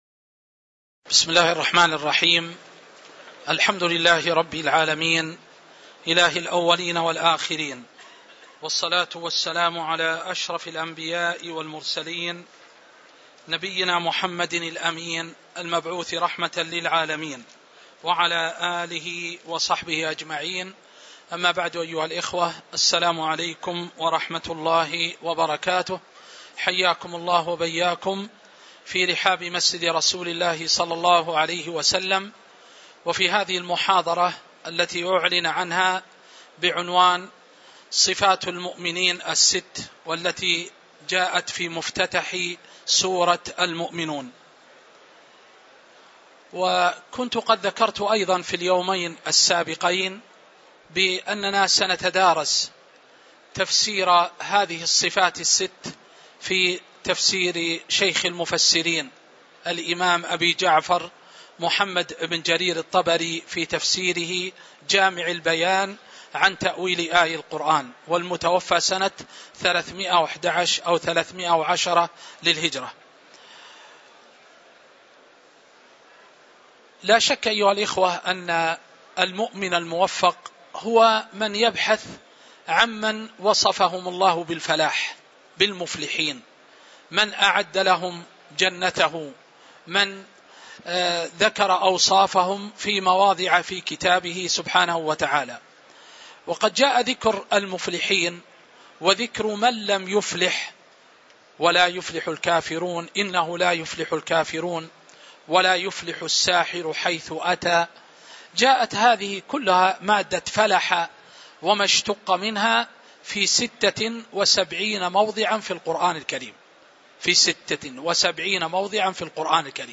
تاريخ النشر ٢ ذو الحجة ١٤٤٤ هـ المكان: المسجد النبوي الشيخ